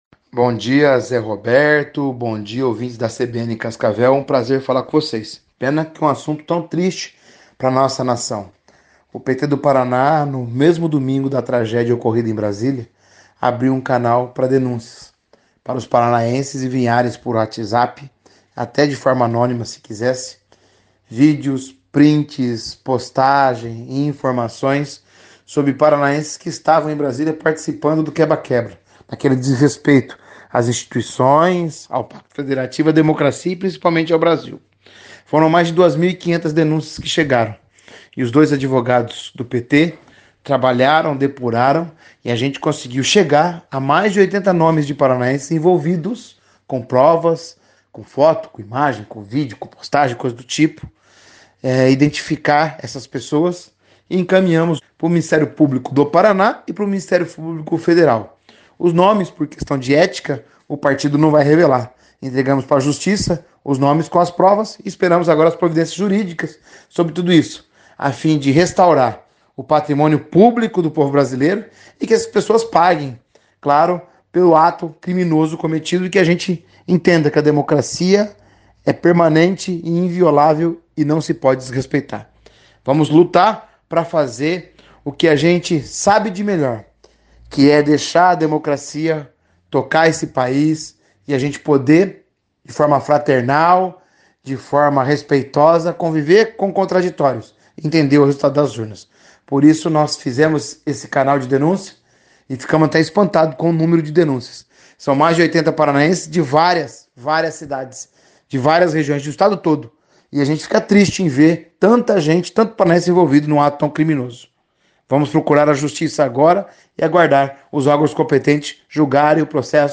Em entrevista à CBN Cascavel nesta quinta-feira (12) o deputado estadual Arilson Chiorato, presidente do PT do Paraná, comentou sobre o levantamento feito pelo partido que aponta mais de 80 nomes de paranaenses envolvidos nos atos de vandalismo em Brasilia, no último domingo (08).
Player Ouça Arilson Chiorato, presidente do PT-PR